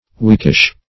Weakish \Weak"ish\, a. Somewhat weak; rather weak.